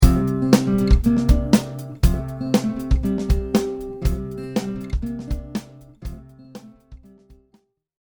A guitar locking perfectly with drums or any percussion instrument creates a tight sound and the precise timing adjustments create a treat for the ears.
With good timing
Skill-3-Timings-Correct.mp3